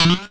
cartoon_boing_retro_jump_02.wav